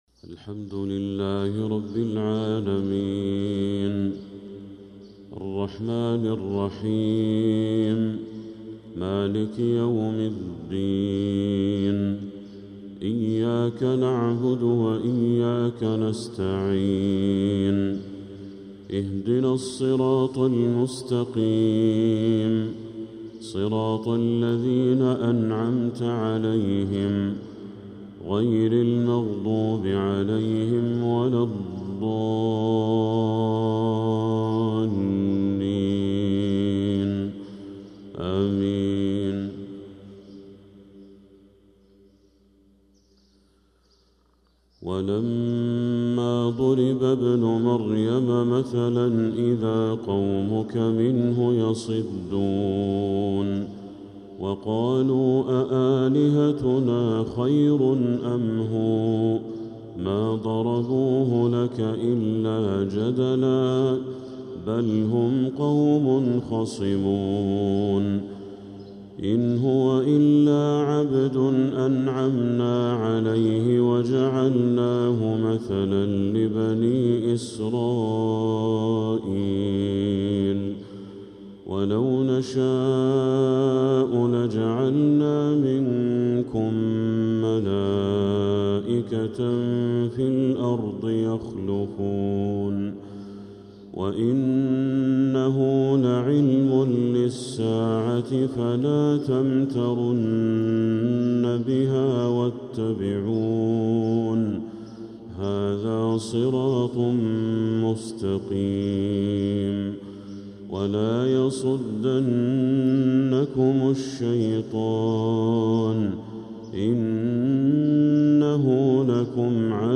فجر السبت 4-7-1446هـ خواتيم سورة الزخرف 57-89 | Fajr prayer from Surat Az-Zukhruf 4-1-2025 🎙 > 1446 🕋 > الفروض - تلاوات الحرمين